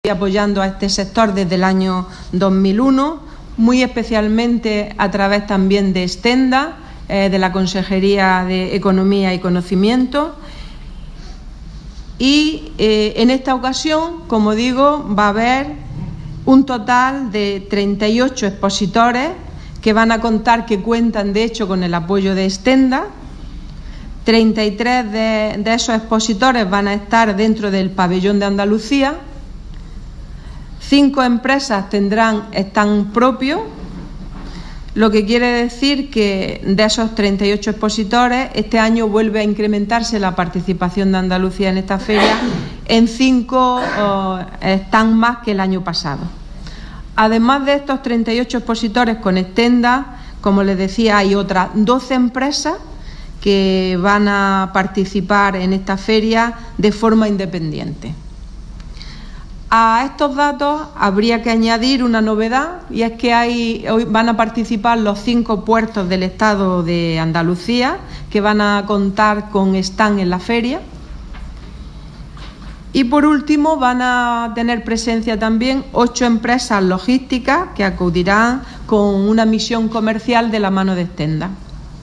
Declaraciones de Carmen Ortiz sobre presencia andaluza en Fruit Logistica 2017